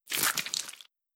Flesh Hits